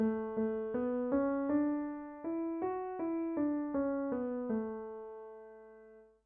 Major keys can be described as happy, or bright.
Example 2: Major Key Melody
It’s actually the same melody – the raised third changes it from minor to major!Ex 2When you arrive at the tonic in a minor key, you can think of it as either “do” or “la”.